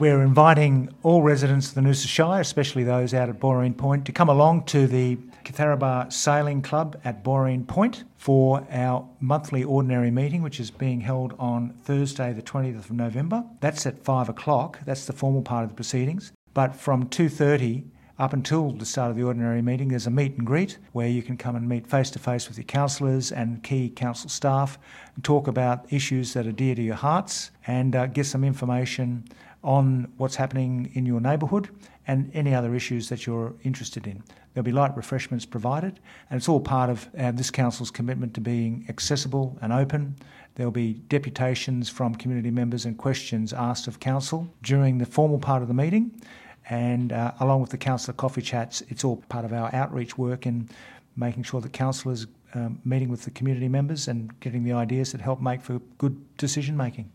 Mayor Frank Wilkie discusses the upcoming Ordinary Meeting at Boreen Point: Mayor-Frank-Wilkie-Ordinary-Meeting-at-Boreen-Point.mp3
mayor-frank-wilkie-ordinary-meeting-at-boreen-point.mp3